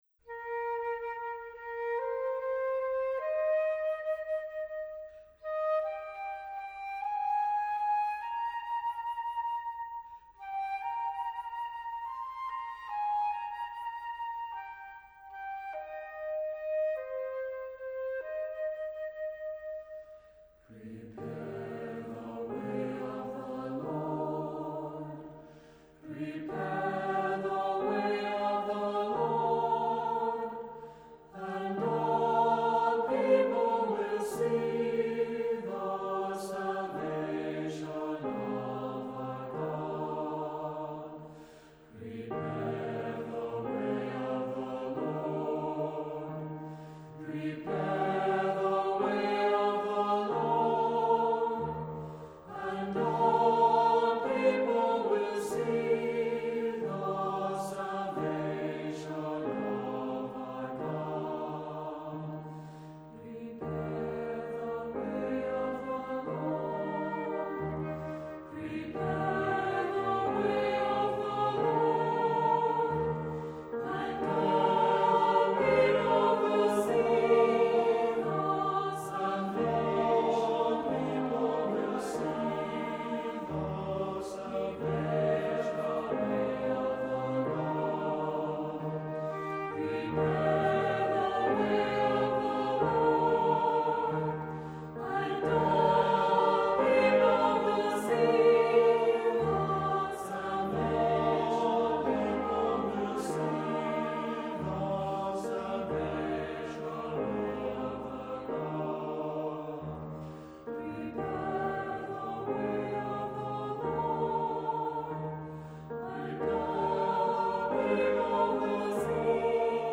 Voicing: SATB; Cantor